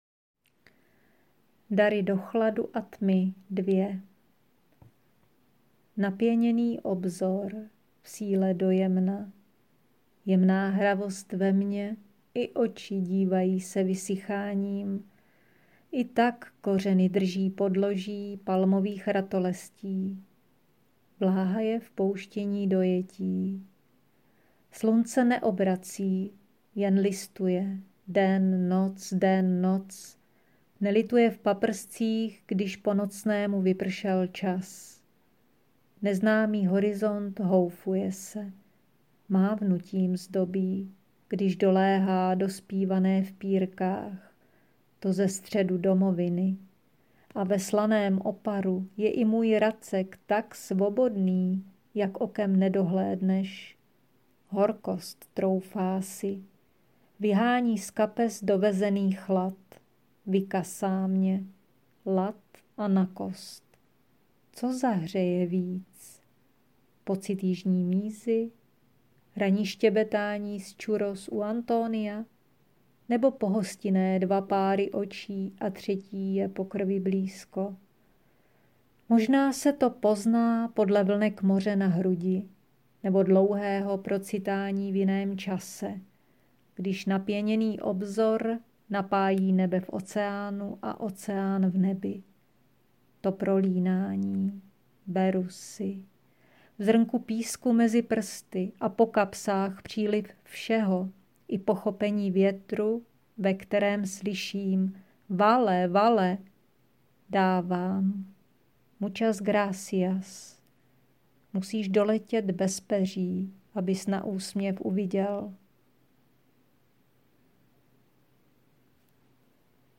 přílivová jinočasová poezie... umocněná dokonalým přednesem... obohacující